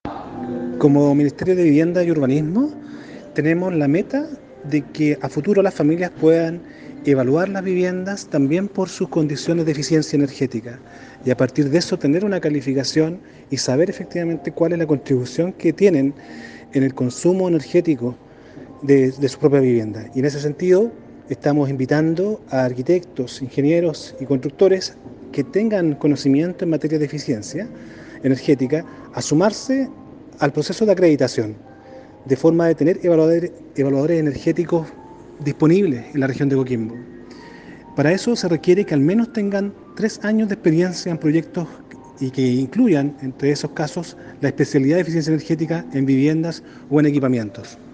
Director Serviu – Angelo Montaño